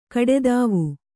♪ kaḍedāvu